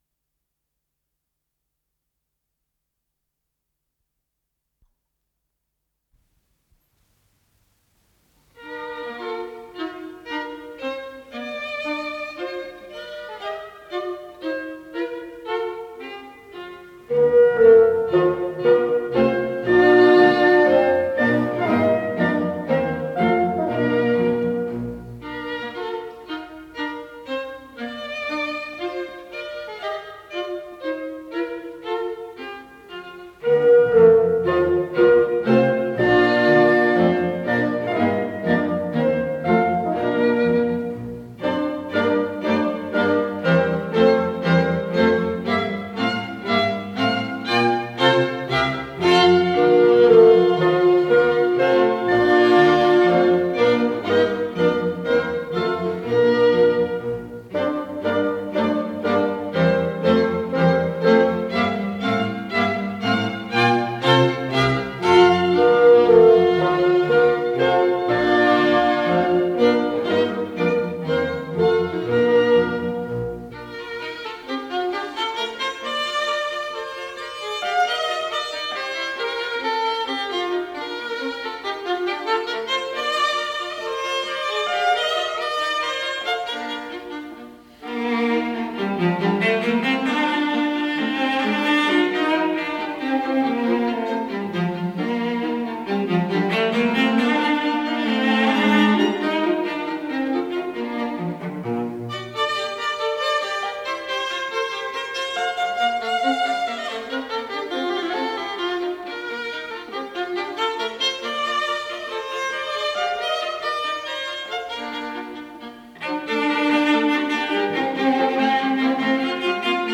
Тема с вариациями, Анданте
скрипка
альт
виолончель
контрабас
кларнет
валторна
фагот
ВариантДубль моно